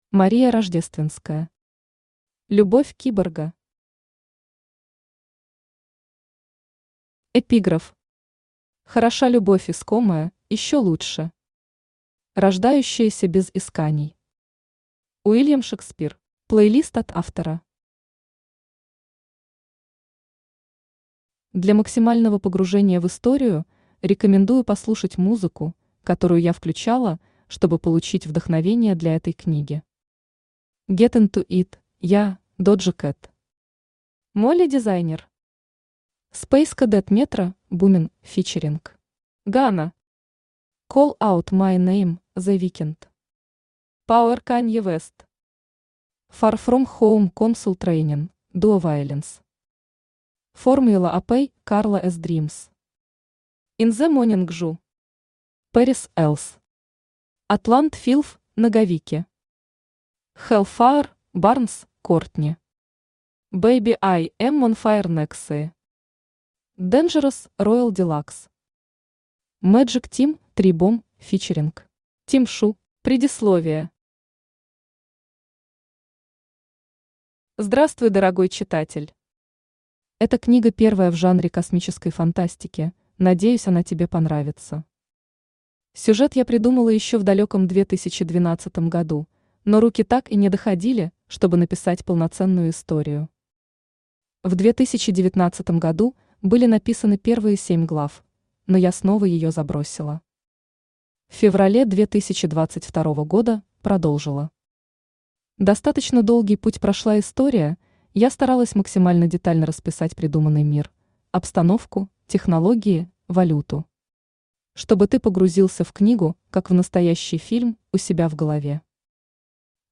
Аудиокнига Любовь киборга | Библиотека аудиокниг
Aудиокнига Любовь киборга Автор Мария Рождественская Читает аудиокнигу Авточтец ЛитРес.